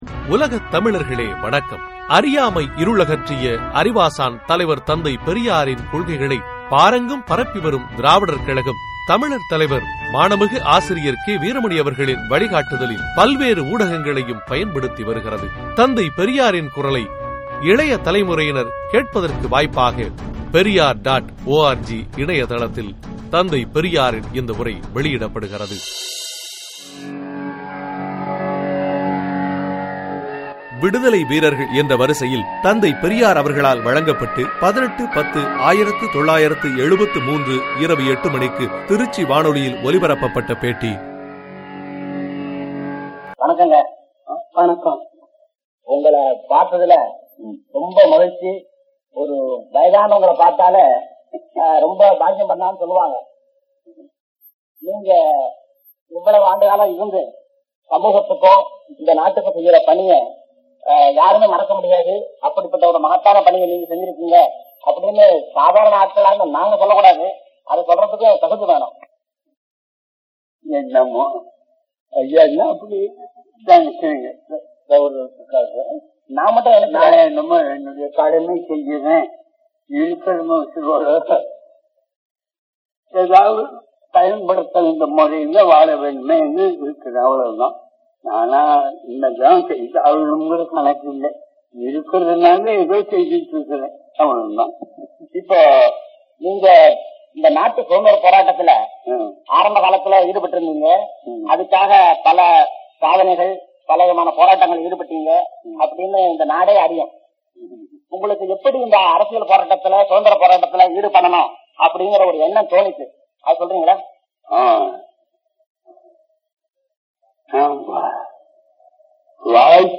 திருச்சி வானொலியில் பெரியார் ஆற்றிய உரை
trichy_radio_(low).mp3